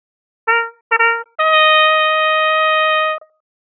brass fanfare hps hpsModel sinusoidal sms sms-tools synthesis sound effect free sound royalty free Memes